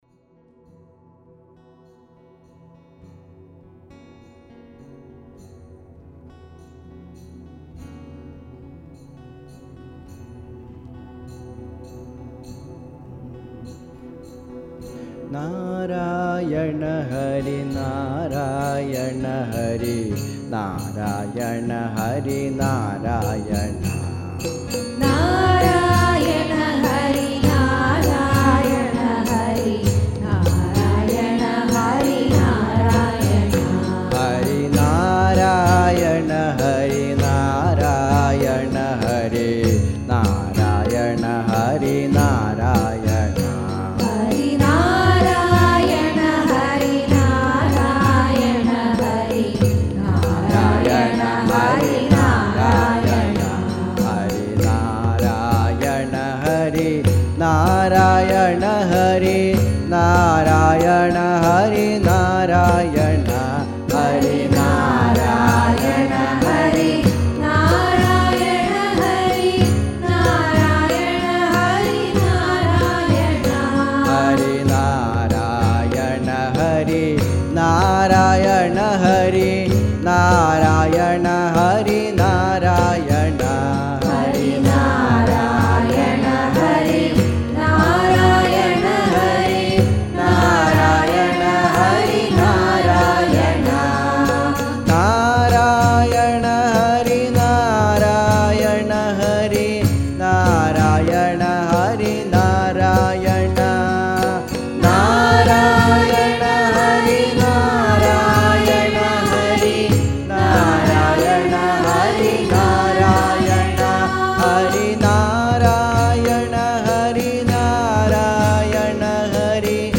Audio recording of Janmastami celebration at SAT Temple; includes reading in English of selected verses from Bhagavad Gita, and bhajans by devotees to Lord Krishna